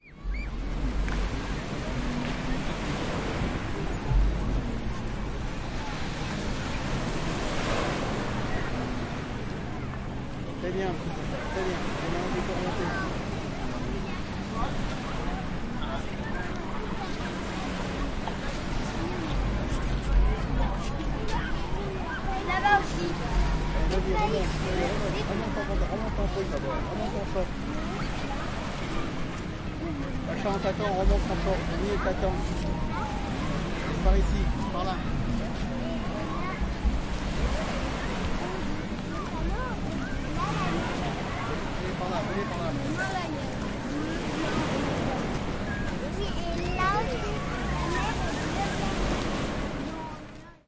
描述：一些海浪的记录击中了芬兰堡的岩石
标签： 沙滩 海浪 现场 录音 海边
声道立体声